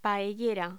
Locución: Paellera
voz